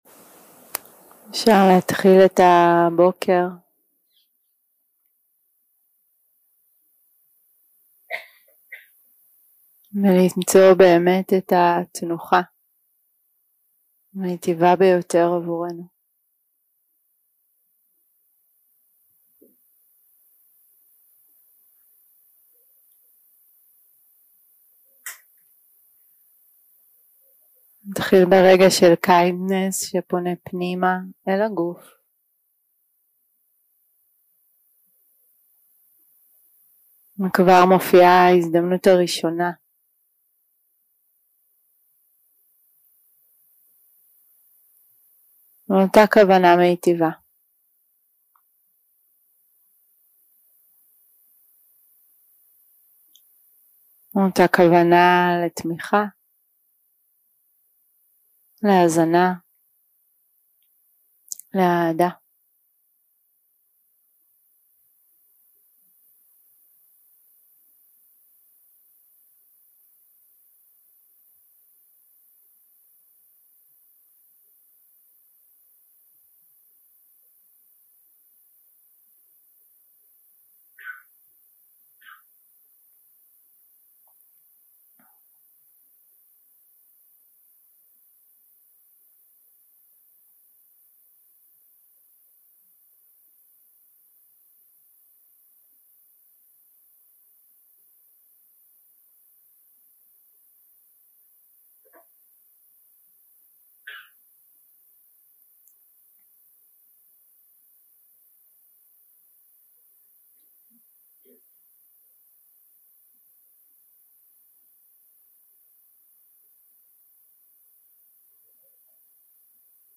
day 6 - recording 18 - Early Morning - Guided Meditation
Dharma type: Guided meditation שפת ההקלטה